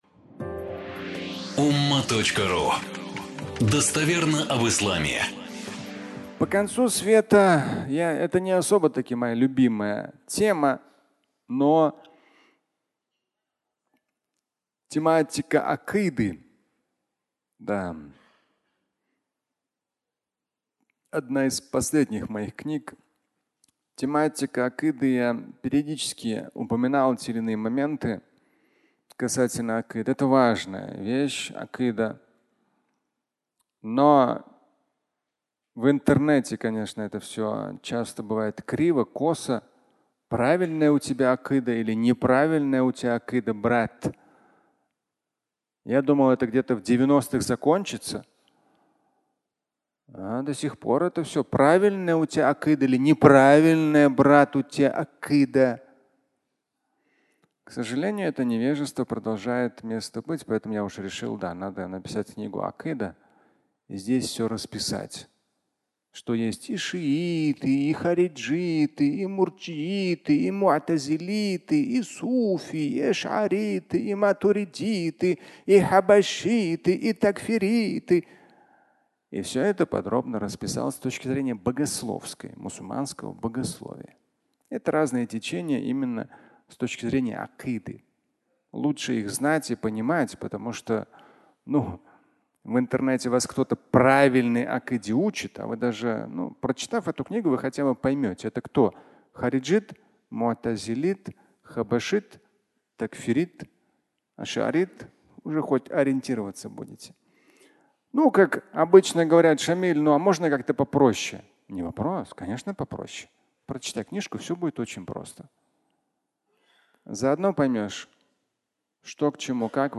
Конец Света (аудиолекция)